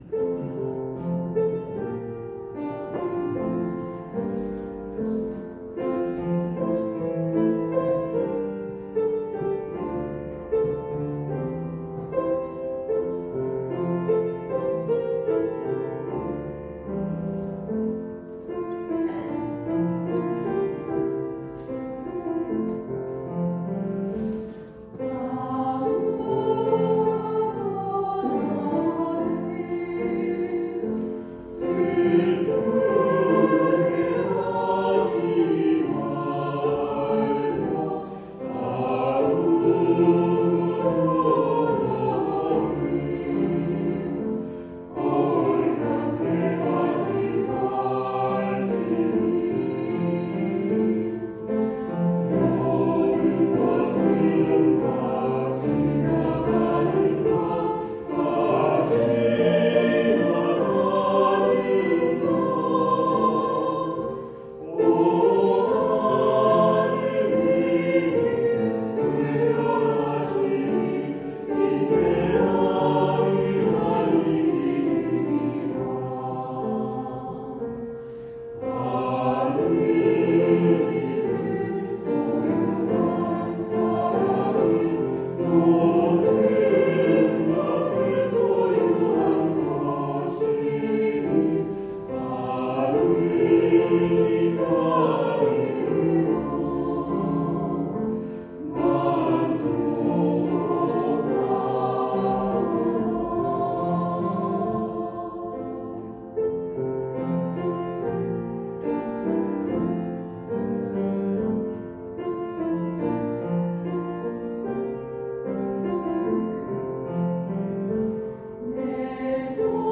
축가
^^♥ 26.3.15 아무것도 너를.m4a3.39MB▲ 1층 녹음파일 입니다.^^